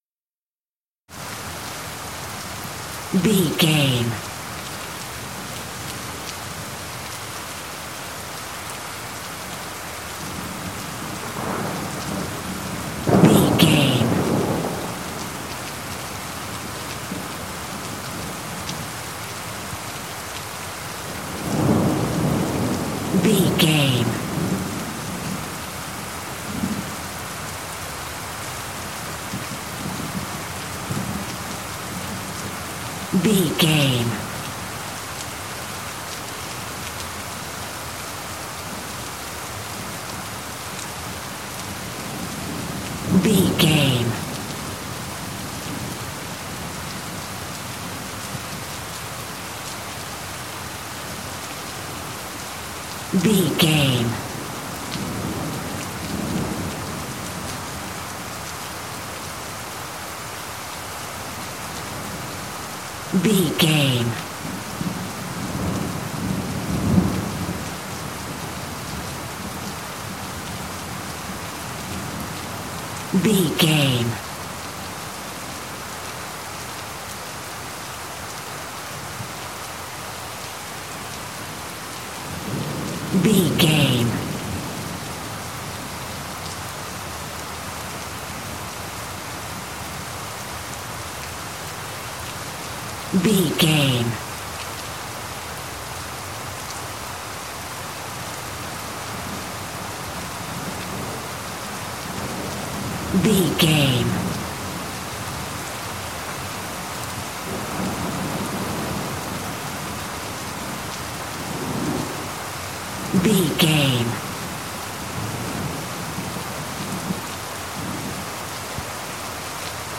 City rain heavy thunder
Sound Effects
urban
ambience